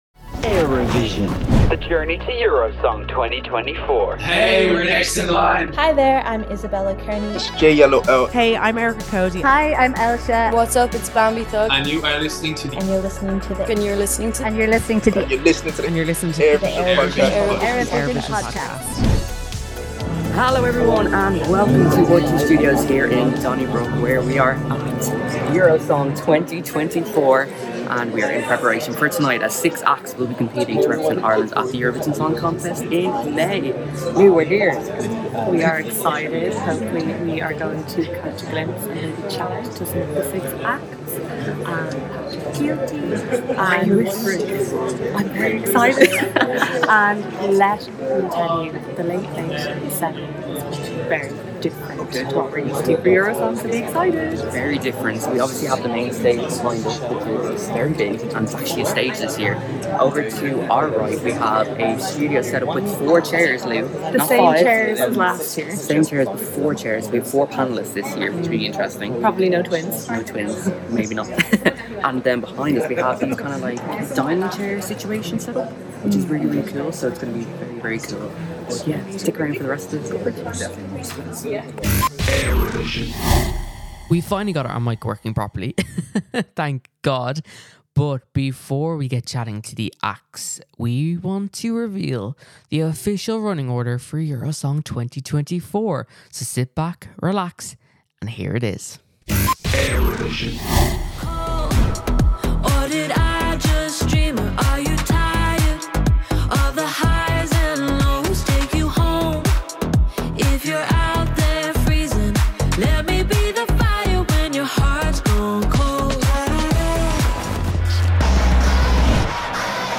They'll be chatting to all six artists competing to represent Ireland at Eurovision, plus we'll grab a few words from our new host - Patrick Kielty. We've also got the exclusive reveal of the official running-order for tonight's show.